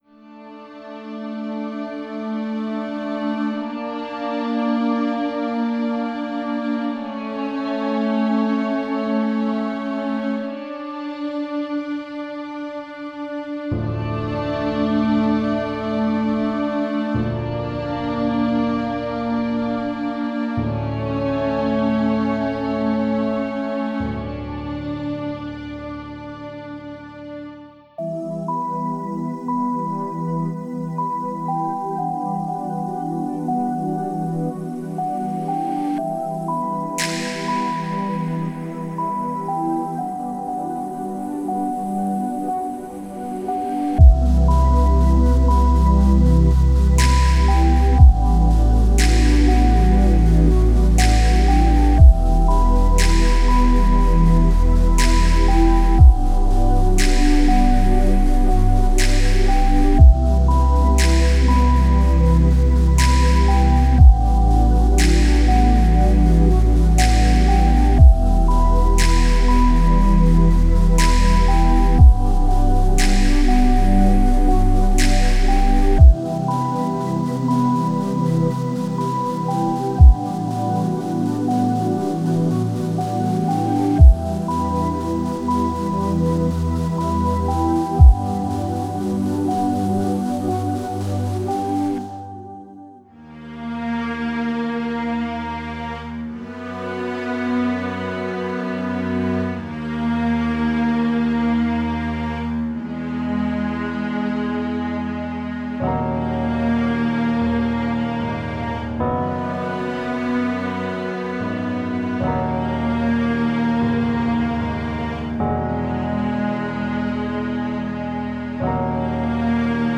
Ambient Cinematic / FX